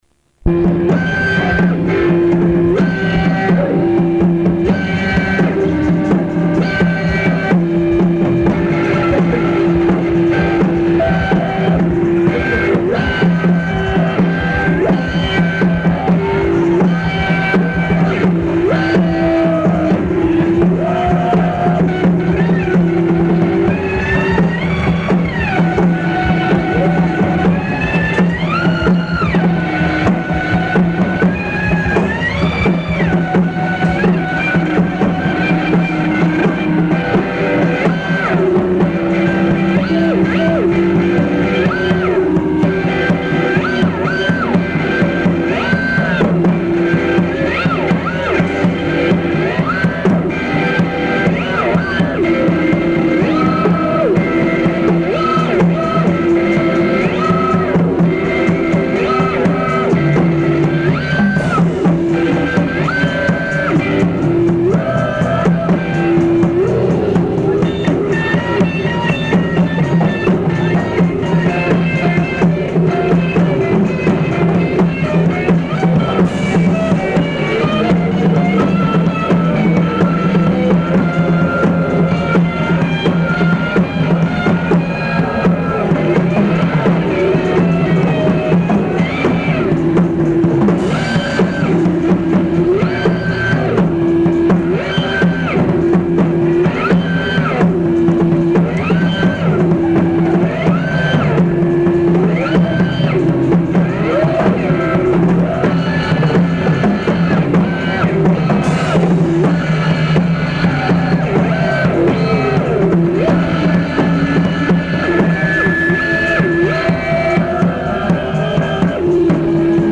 Location: Big V's